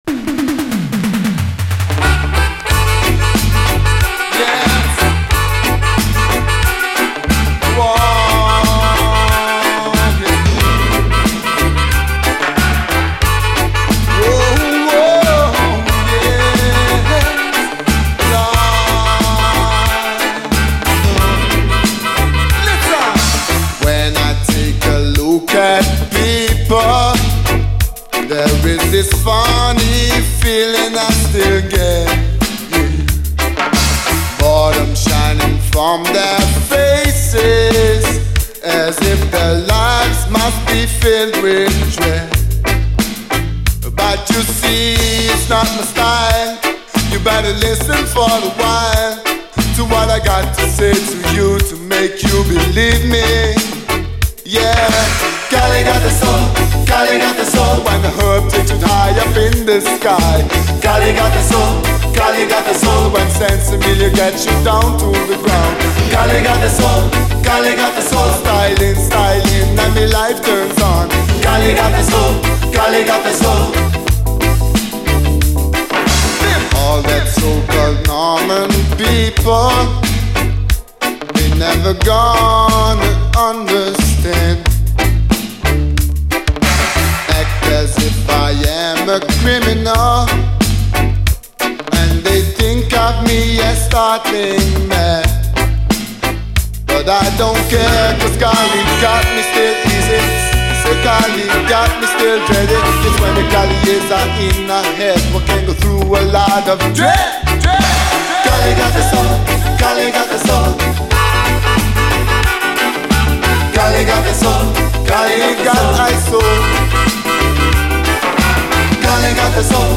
REGGAE, 7INCH
最高に楽しいオランダ産キラー80’Sルーツ・レゲエ！
後半にはヴォーカルがDEE-JAY的に変化。